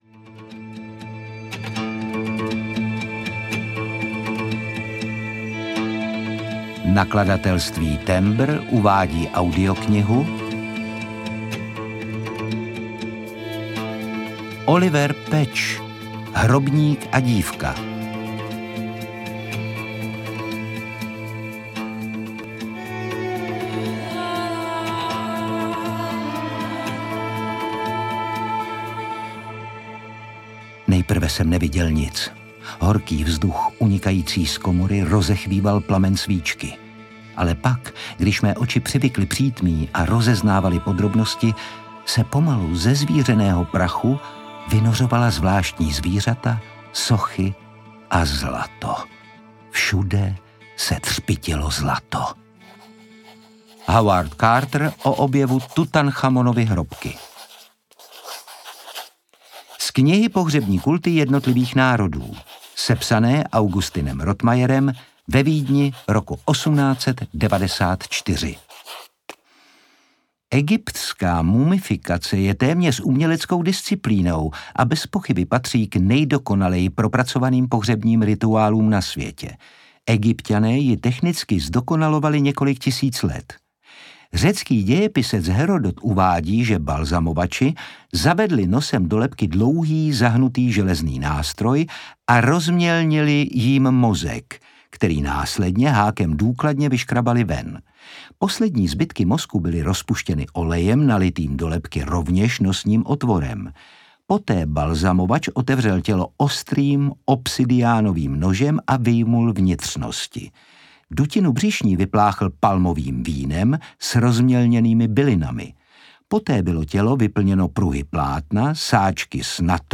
Audiobook
Read: Otakar Brousek Jr.